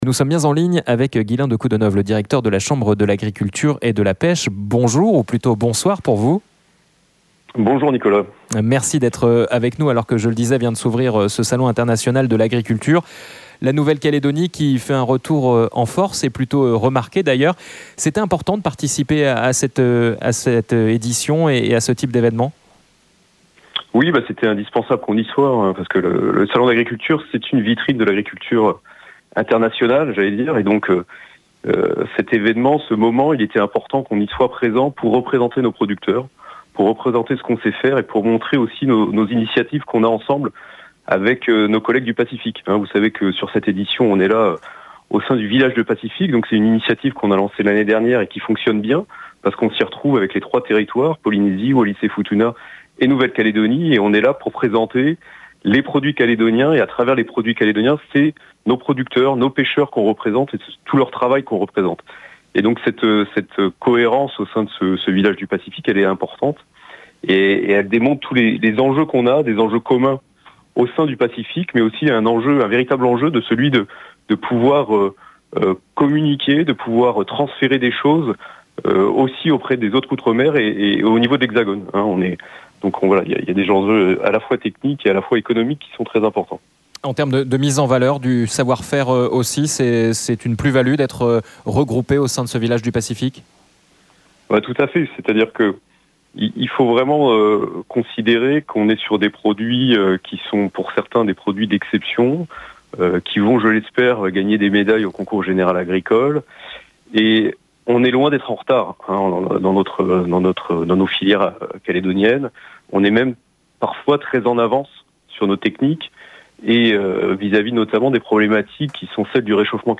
Il s'exprime depuis Paris, où le salon de l'agriculture et de la pêche s'est ouvert ce weekend, avec un stand calédonien sur place.